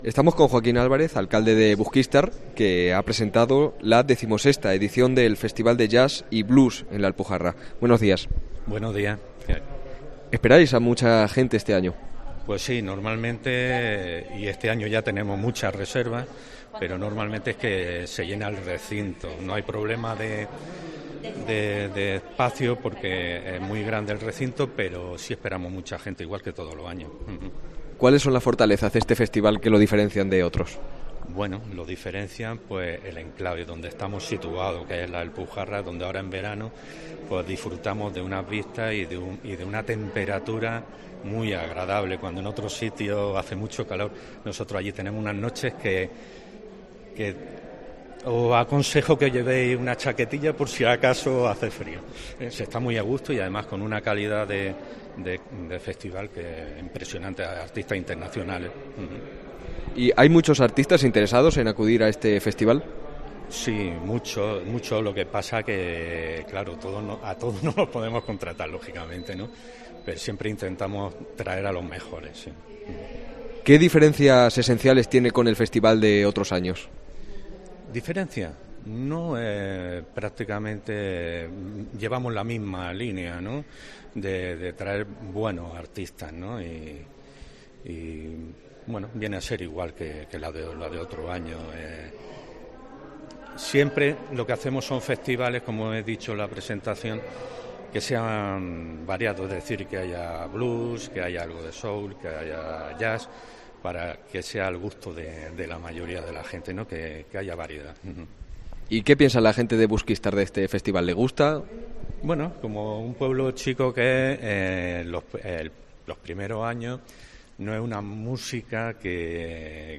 Entrevista a Joaquín Álvarez, alcalde de Busquístar